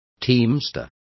Complete with pronunciation of the translation of teamsters.